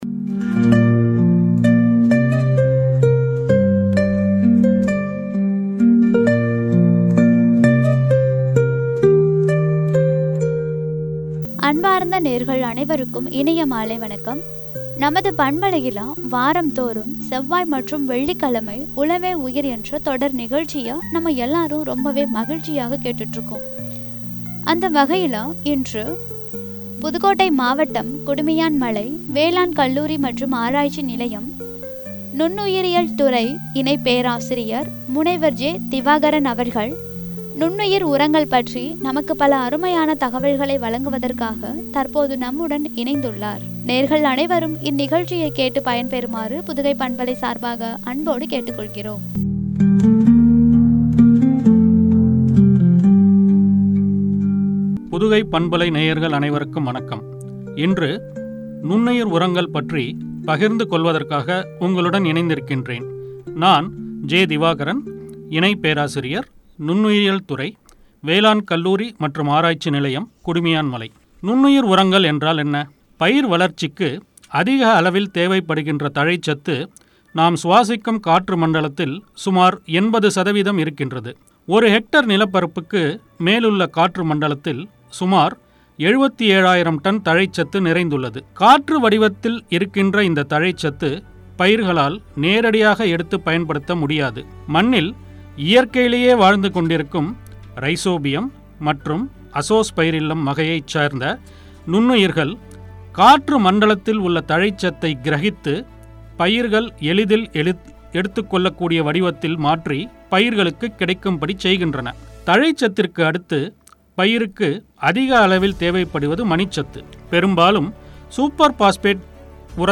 “நுண்ணுயிர் உரங்கள்” என்ற தலைப்பில் வழங்கிய உரை.